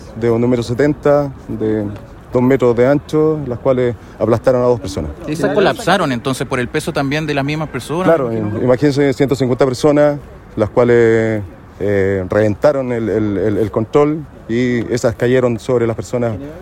Por su parte, el general Álex Bahamondes informó que un número de aproximadamente 150 personas intentaron acceder sin su entrada, por lo que provocaron el desplome de la reja sobre las víctimas.